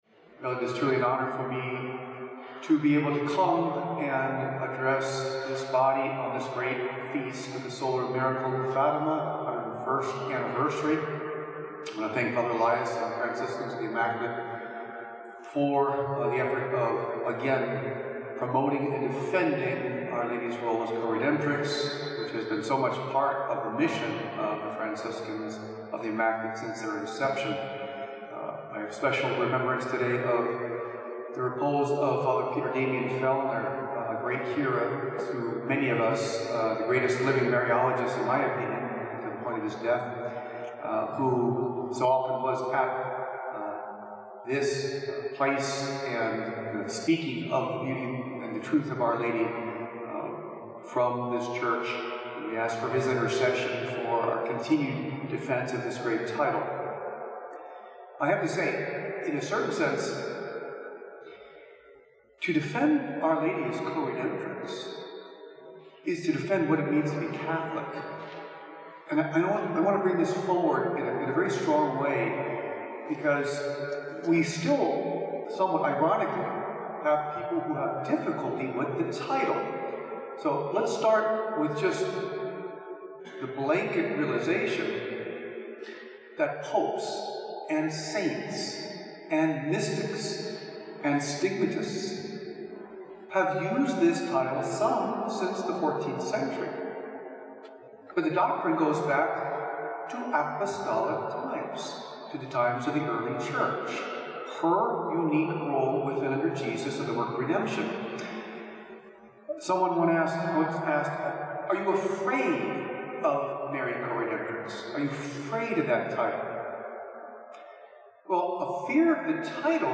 eighth talk
at the Marian Coredemption symposium at the Shrine of Our Lady of Guadalupe, La Crosse, WI in 2018 on how fitting it is to proclaim the dogma of Mary as Coredemptrix given the nature of the difficulties of our age